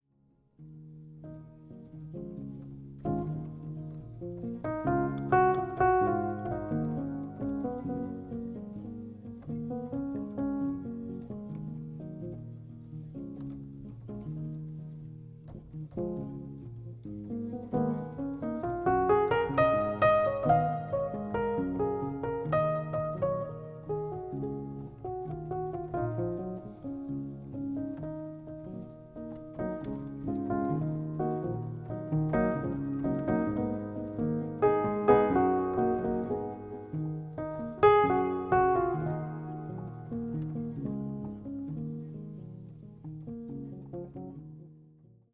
ポスト・クラシカル
一歩引いたところから自分を、世界を見る、静かな熱量。